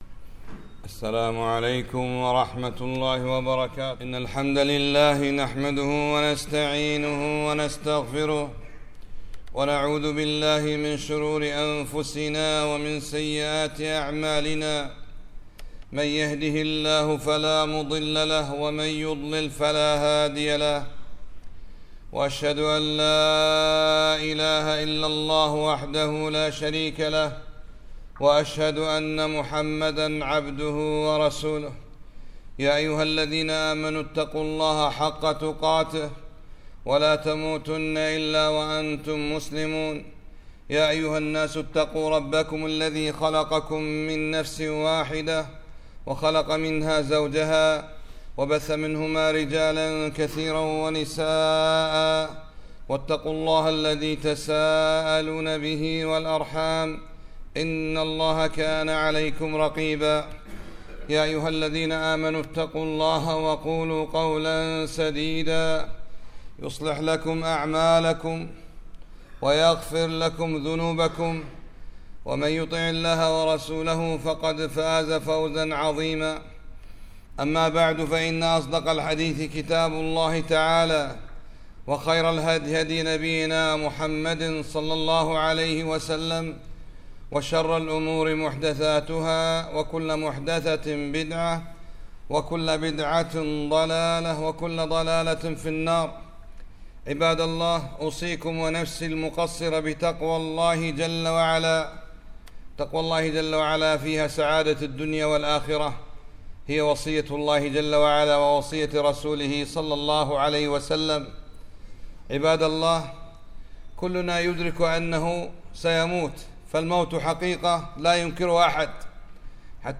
خطبة - قال النبي ﷺ ( إذا مات الإنسان انقطع عمله إلا من ثلاثة..)